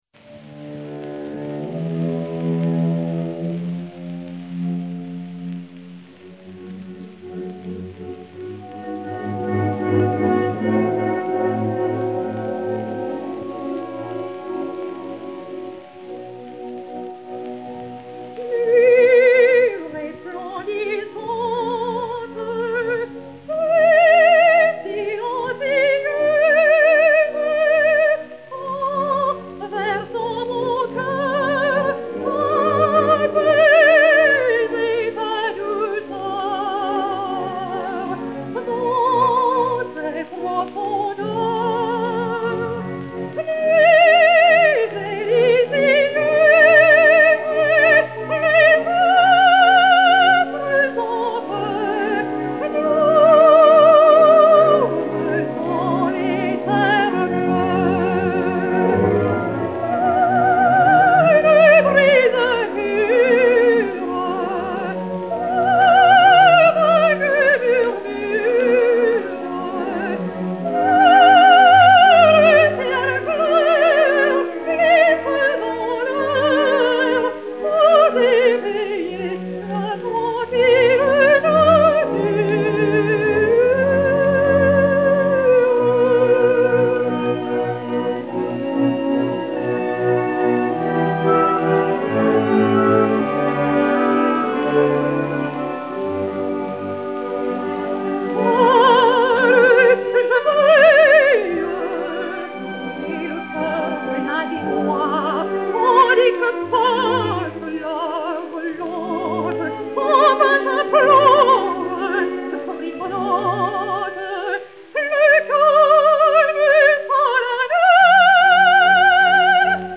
Cantilène "Nuit resplendissante et silencieuse"
et Orchestre